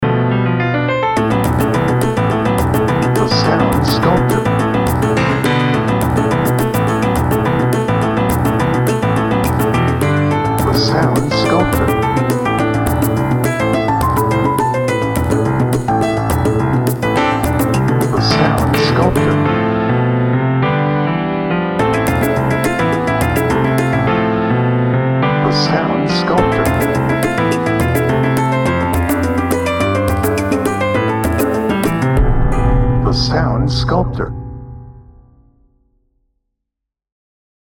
Chase
Speed
Tense